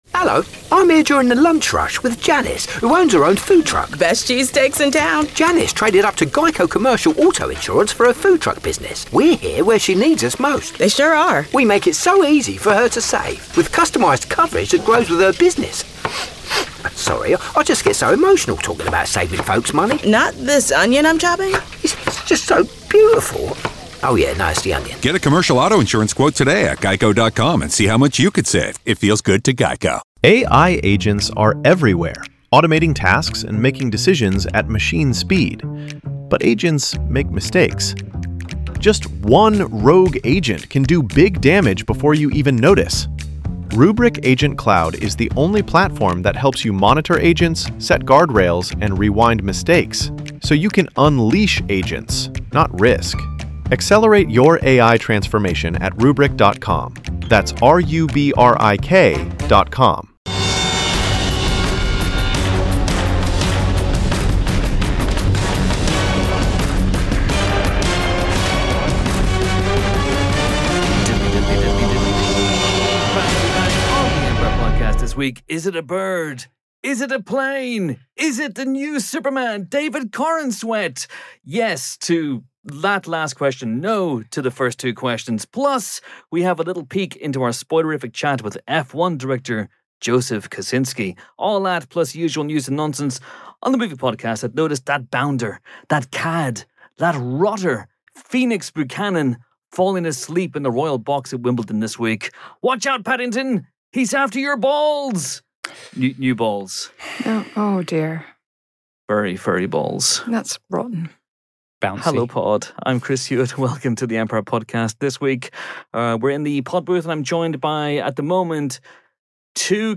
This week's episode of the Empire Podcast is dominated by bald baddies. Namely, a group discussion of the hairless horrors who have been tormenting do-gooders in all kinds of movies throughout the years.